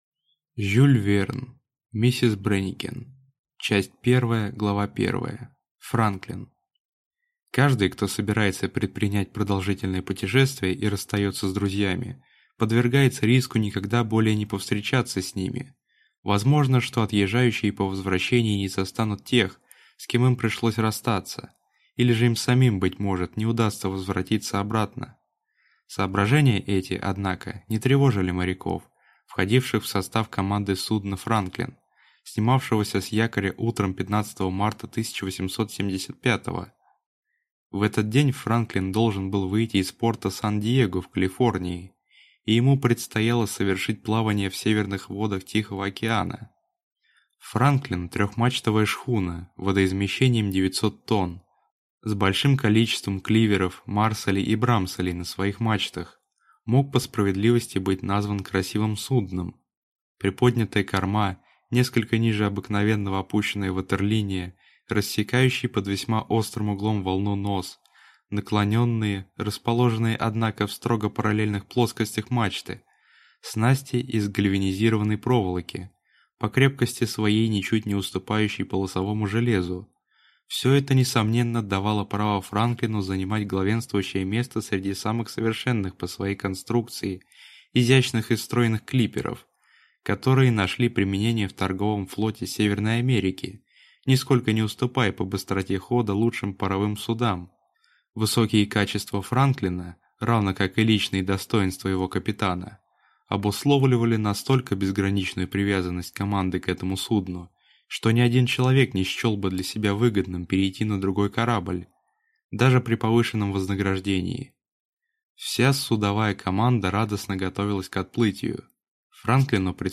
Аудиокнига Миссис Брэникен | Библиотека аудиокниг